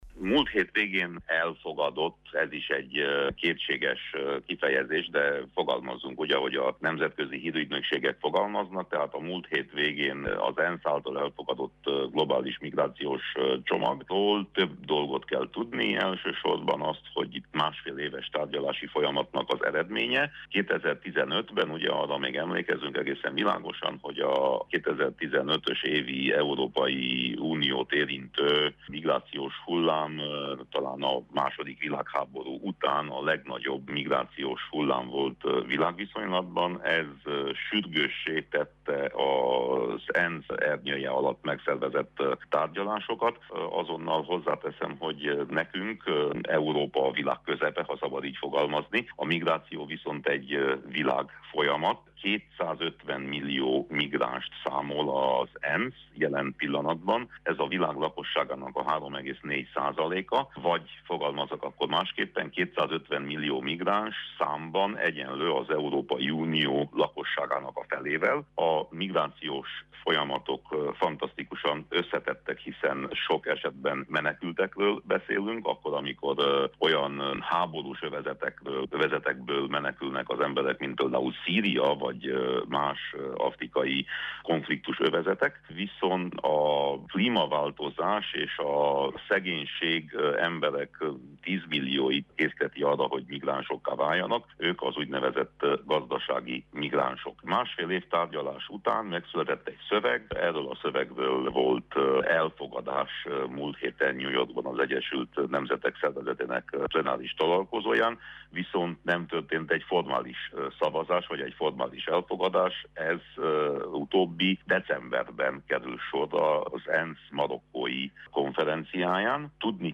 Nekünk Európa a világ közepe, ha szabad így fogalmazni, a migráció viszont egy világfolyamat – nyilatkozta Winkler Gyula Európai Parlamenti képviselő, akit az ENSZ által elfogadott globális migrációs csomag másfél éves tárgyalási folyamatának eredményeiről kérdeztük.
Riporter